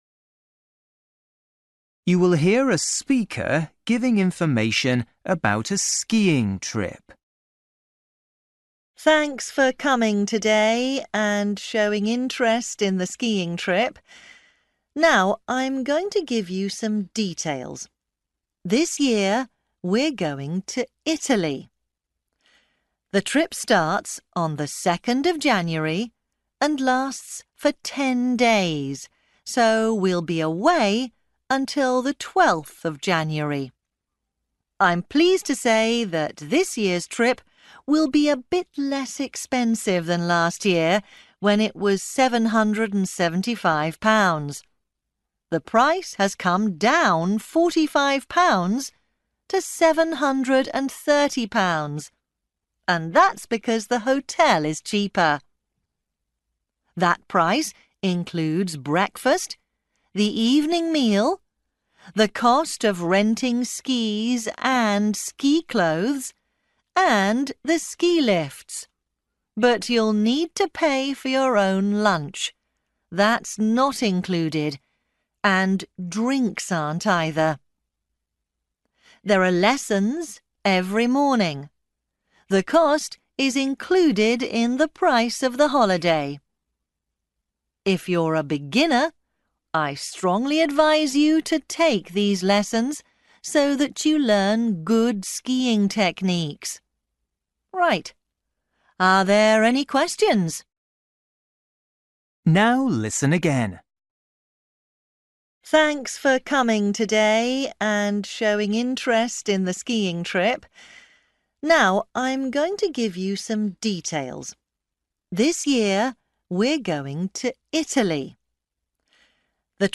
You will hear a speaker giving information about a skiing trip.